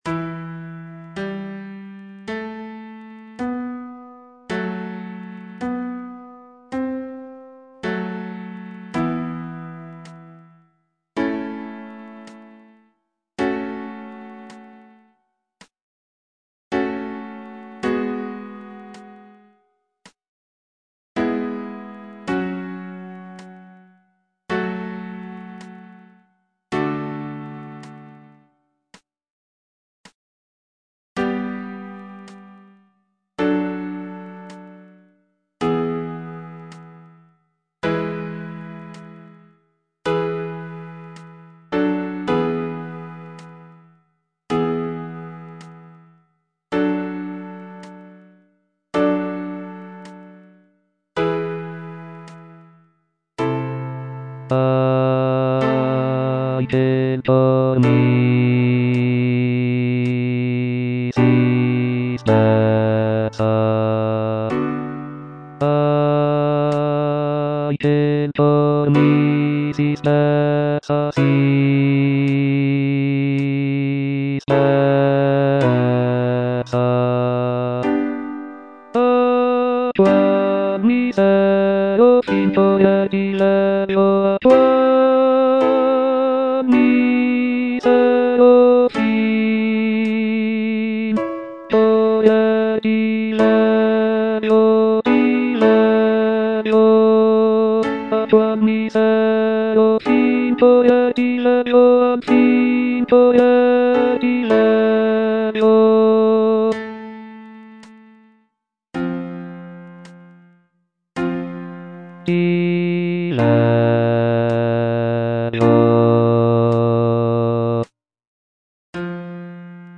C. MONTEVERDI - LAMENTO D'ARIANNA (VERSION 2) Coro II: Ahi! che'l cor mi si spezza - Bass (Voice with metronome) Ads stop: auto-stop
The piece is based on the character of Ariadne from Greek mythology, who is abandoned by her lover Theseus on the island of Naxos. The music is characterized by its expressive melodies and poignant harmonies, making it a powerful and moving example of early Baroque vocal music.